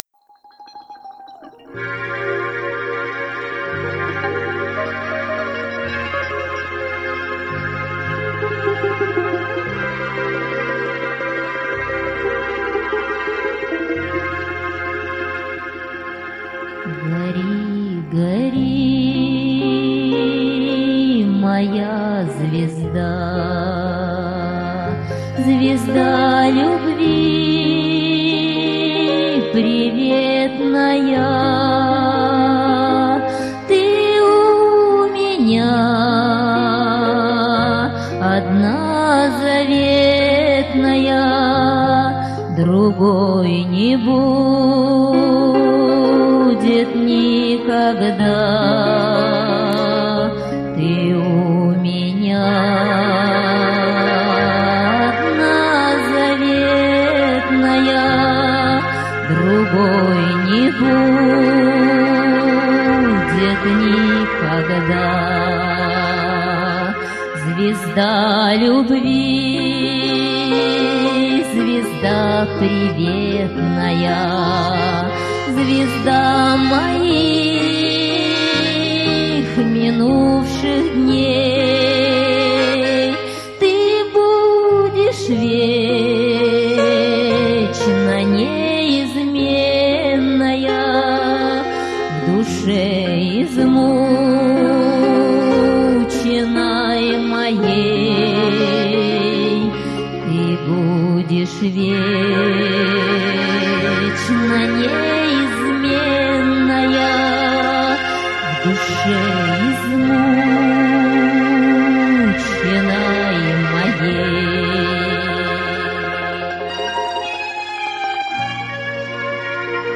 Жанр: Поп-фолк